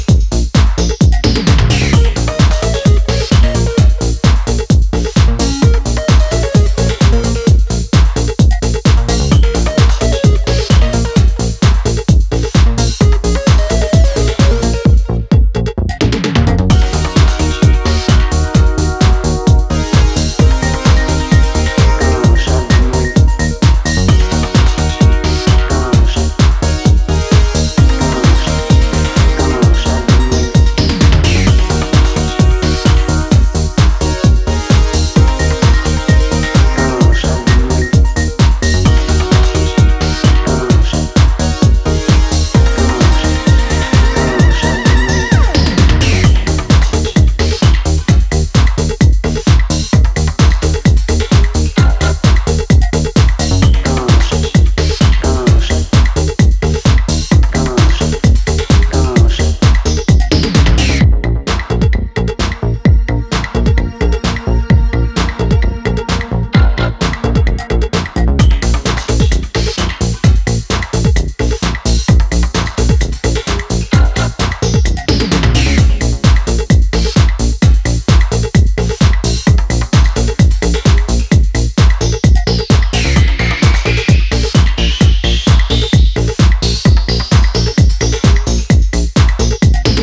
comes in the form of four club-ready cuts.